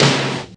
Snaredrum-03.wav